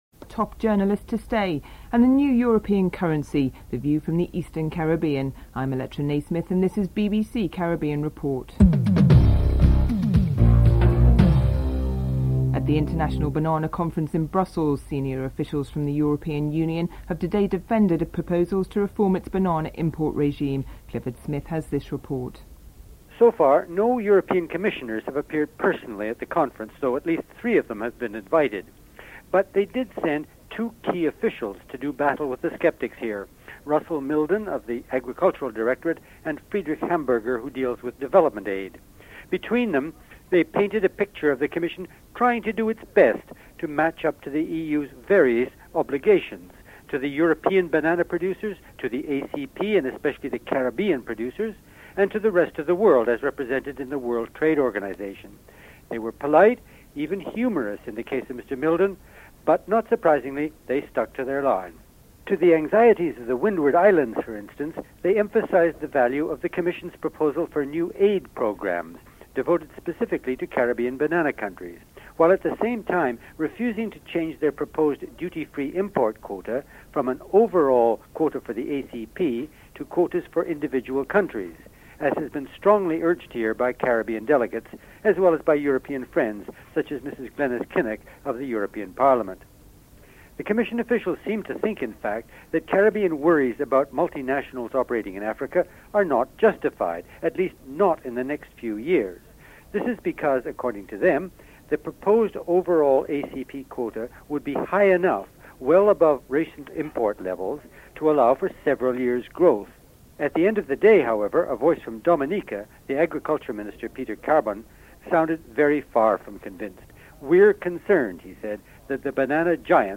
Headlines
8. Recap of top stories (14:20-14:50)